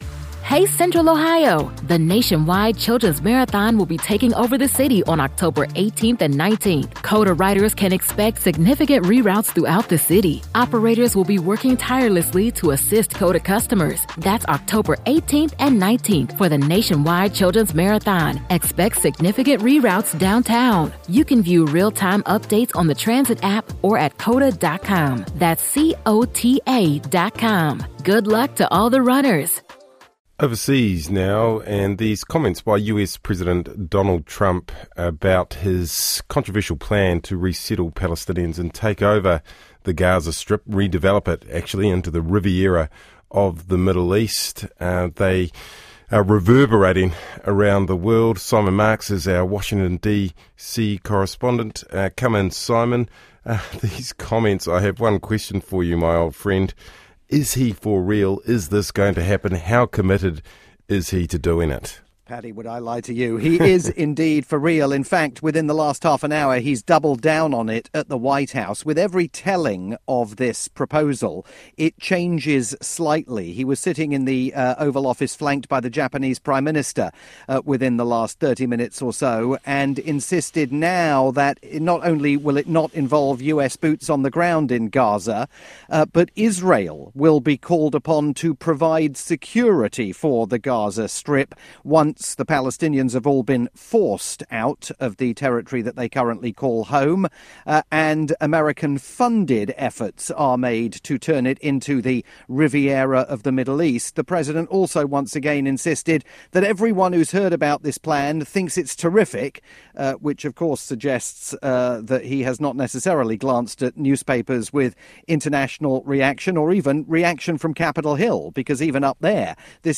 live update for Radio New Zealand's "Saturday Morning", with Patrick Gower hosting.